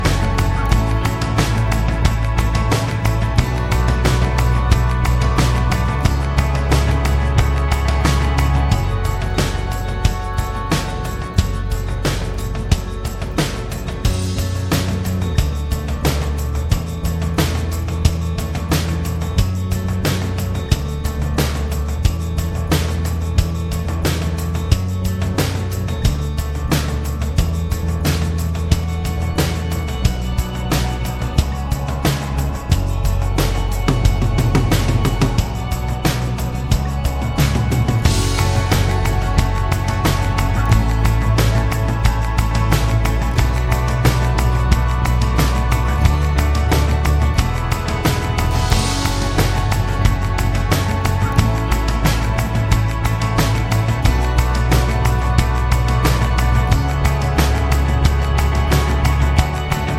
Minus Main Guitar For Guitarists 3:18 Buy £1.50